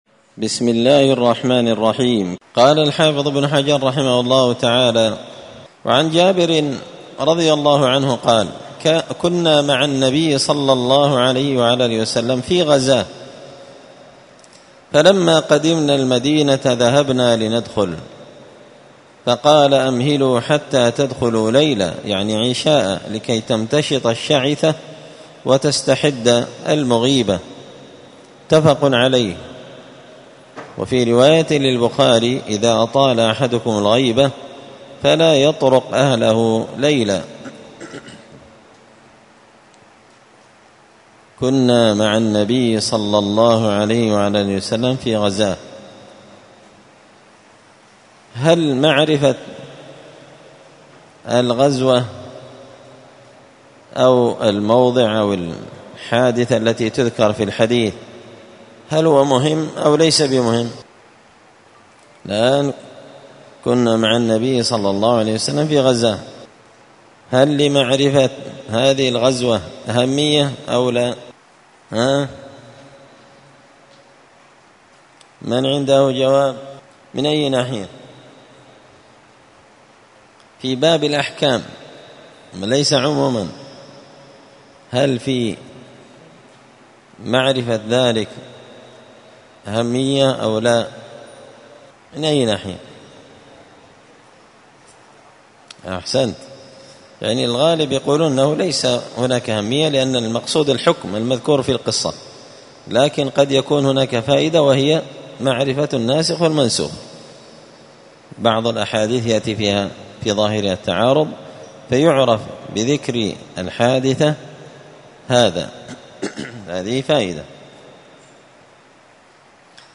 *الدرس 21 تابع أحكام النكاح {باب عشرة النساء}*
مسجد الفرقان_قشن_المهرة_اليمن